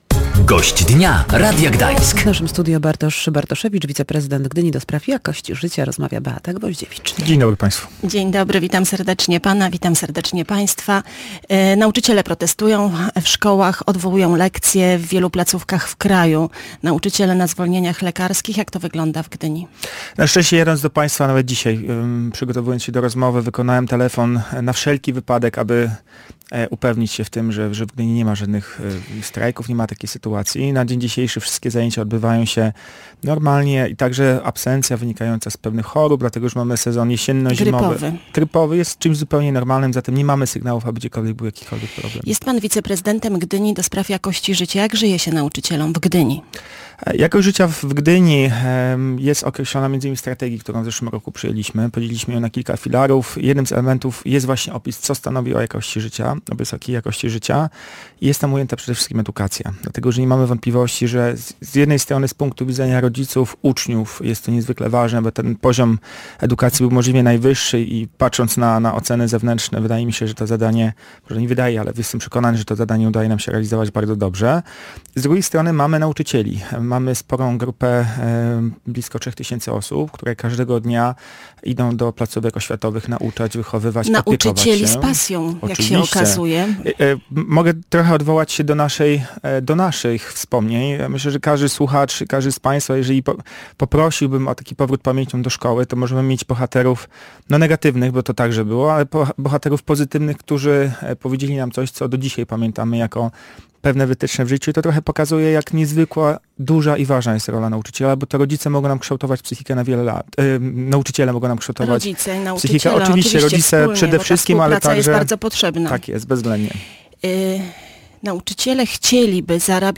Gościem Dnia Radia Gdańsk był Bartosz Bartoszewicz, wiceprezydent Gdyni ds. jakości życia.